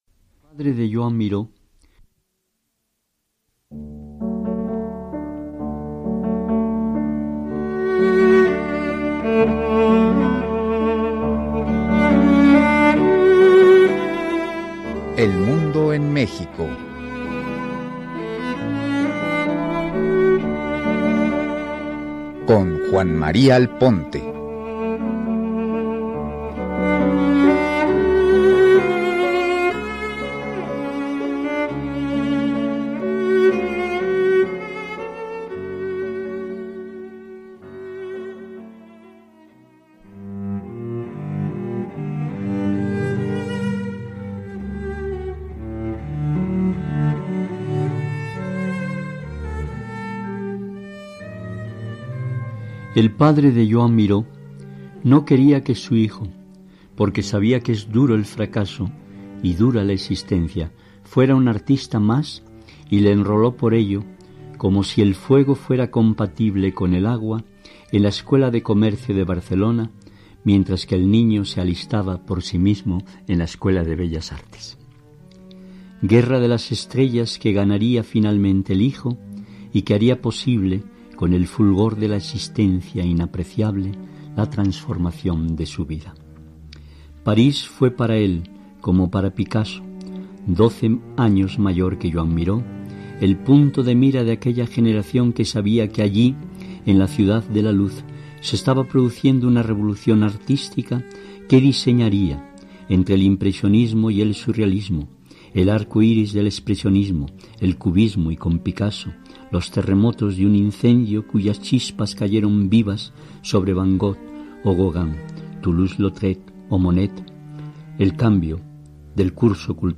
Escucha una semblanza sobre Miró en el programa de Juan María Alponte, “El mundo en México”, transmitido en 1998.